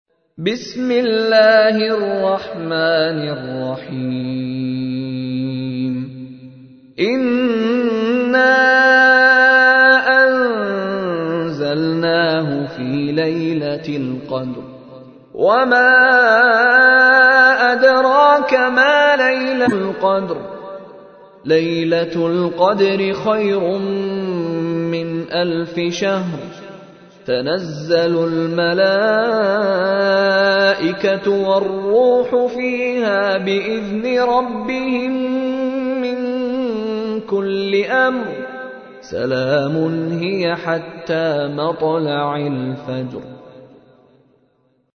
تحميل : 97. سورة القدر / القارئ مشاري راشد العفاسي / القرآن الكريم / موقع يا حسين